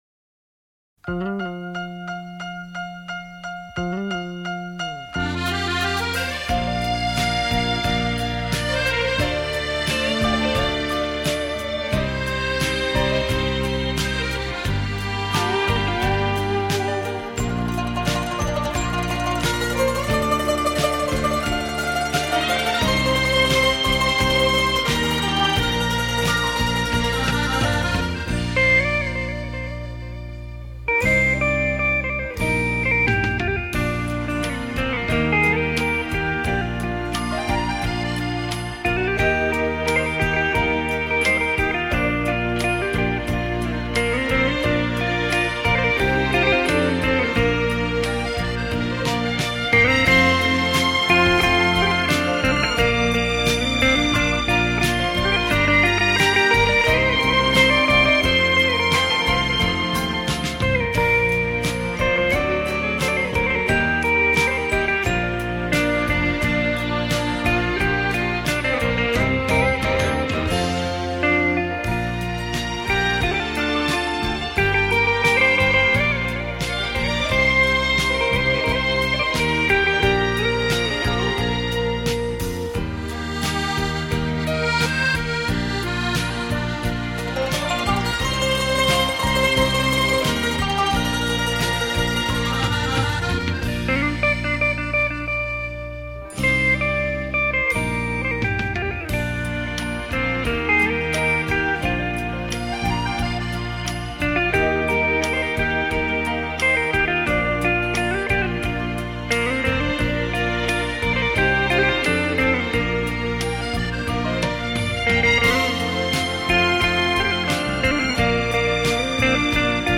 熟悉的旋律。。。
好听的演歌。